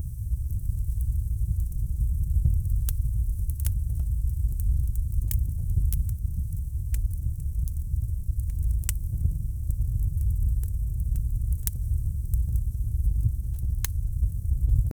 Faint whoosh of combustion. 0:15 Created Dec 22, 2024 4:33 AM Soft rumble of a coal stove burning steadily, faint metallic clink of coal being added, low creak of the stove door, distant winter wind outside. 0:15 Created Dec 24, 2024 4:44 AM
soft-rumble-of-a-coal-gjhfk3am.wav